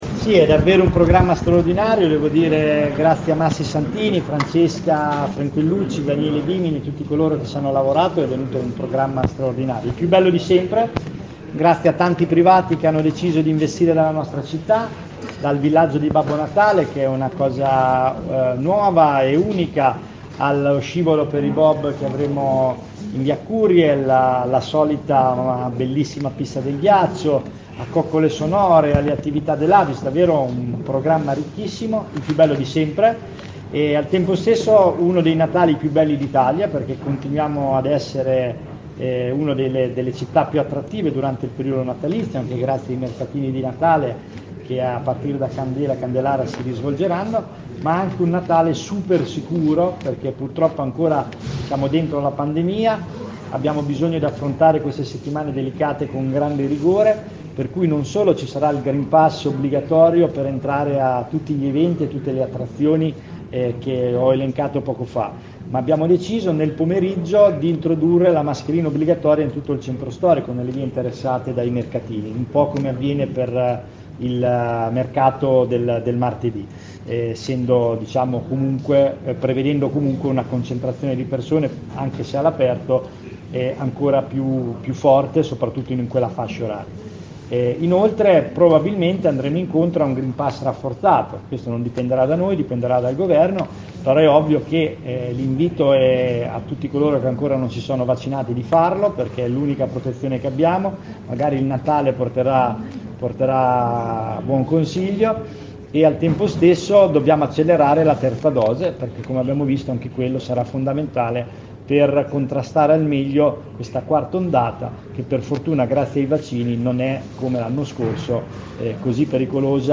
interviste-pesaro-nel-cuor.mp3